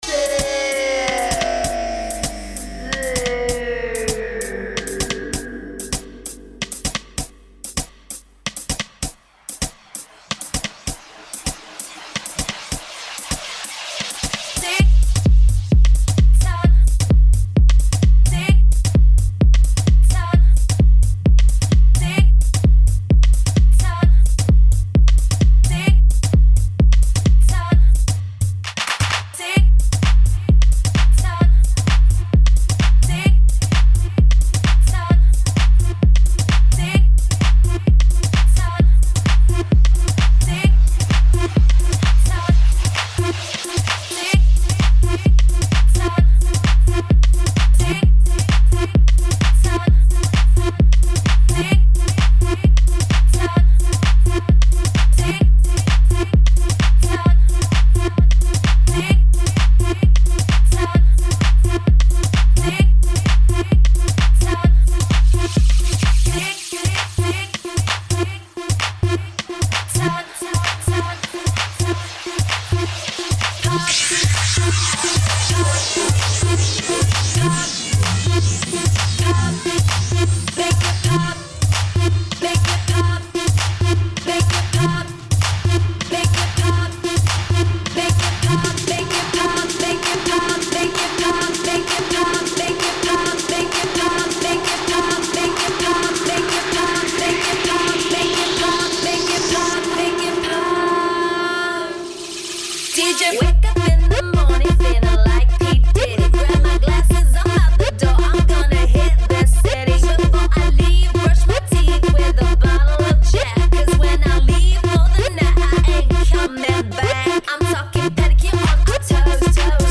I had a very fat bass at my disposal.
Posted in Mashups